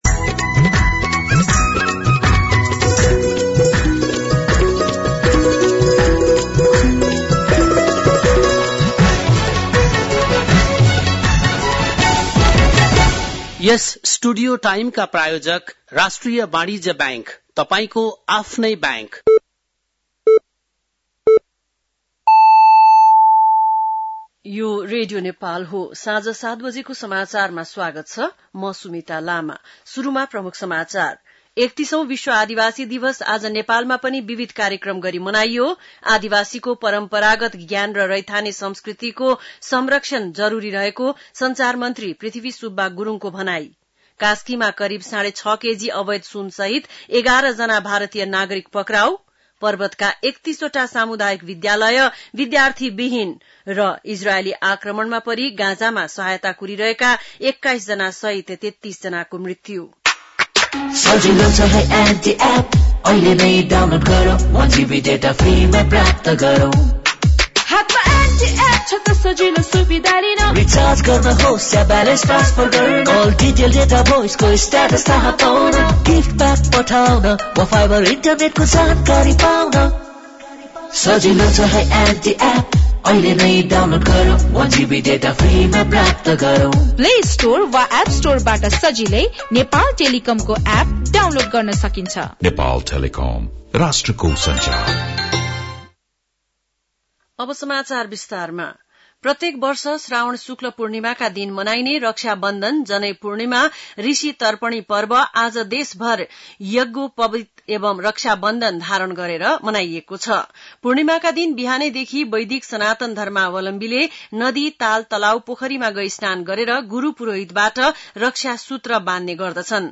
बेलुकी ७ बजेको नेपाली समाचार : २४ साउन , २०८२